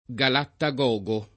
vai all'elenco alfabetico delle voci ingrandisci il carattere 100% rimpicciolisci il carattere stampa invia tramite posta elettronica codividi su Facebook galattagogo [ g alatta g0g o ] s. m. e agg.; pl. m. -ghi — non galattogogo